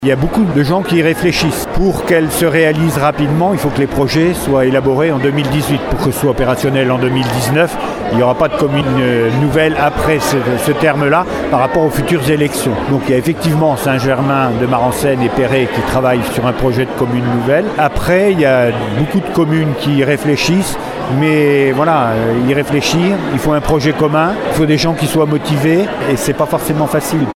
Pas si sûr, selon Jean Gorioux, le président d’Aunis Sud :
Les élus se sont exprimés hier soir à l’occasion des vœux de la CdC Aunis Sud qui se sont tenus symboliquement à La Devise, devant 200 invités et un parterre d’élus locaux.